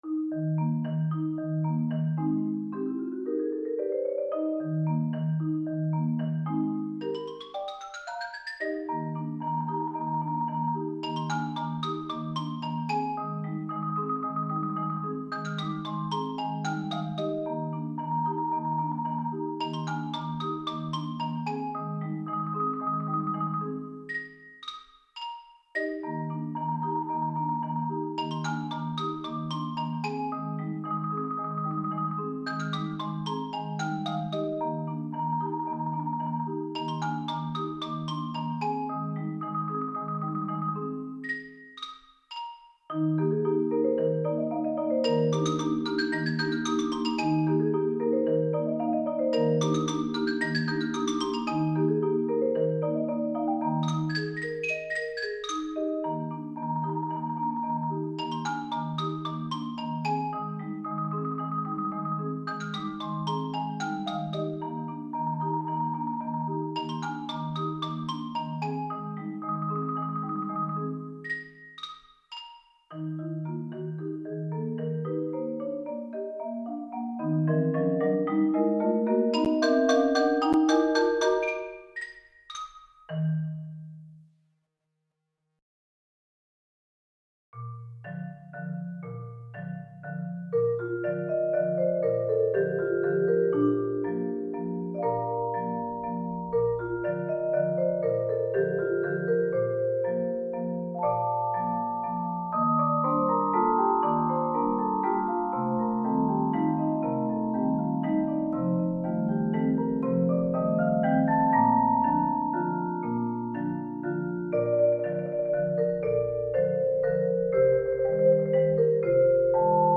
Genre: Percussion Ensemble
# of Players: 4
Player 1: Xylophone, Vibraphone, Glockenspiel, Shekere
Player 3: 4-octave Marimba, Xylophone